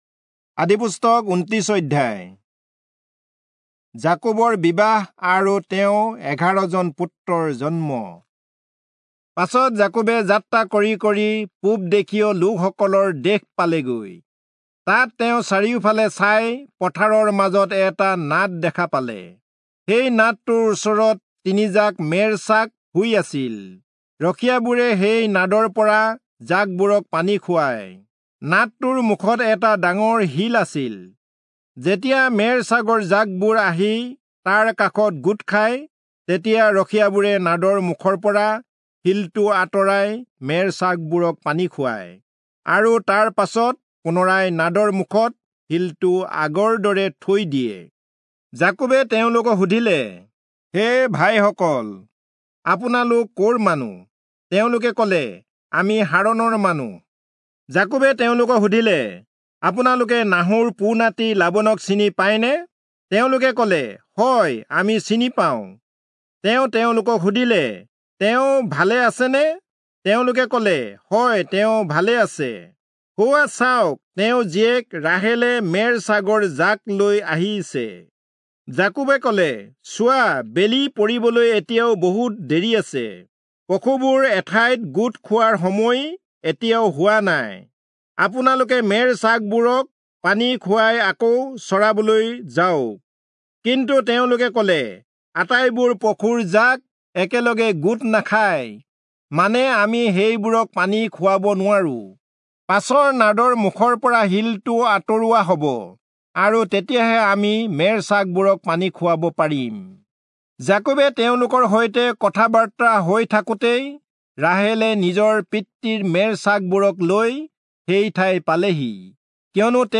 Assamese Audio Bible - Genesis 24 in Ocvml bible version